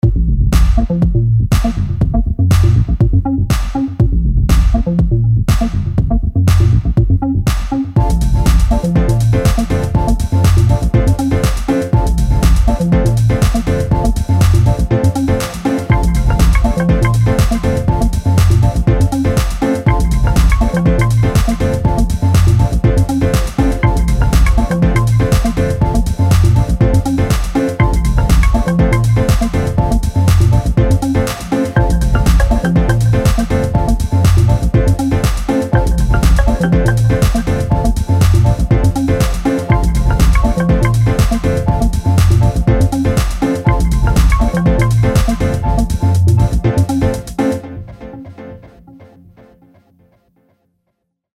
经典的美国 house 音色
源自舞池的美妙键盘、和弦、贝斯和弦乐
开始使用150个适用性强的预设，包括经典的钢琴、风琴、stab、贝斯和深情的 pad
所有声音都忠实地采样自 house 最具标志性的合成器
从卓越的和弦到脉动的低音，传递真实的90年代 house 氛围，为面向未来的俱乐部声音创造新的融合。